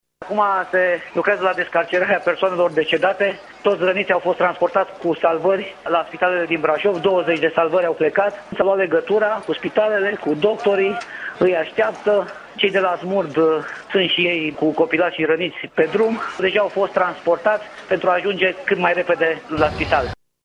Prefectul județului Brașov, Ciprian Băncilă: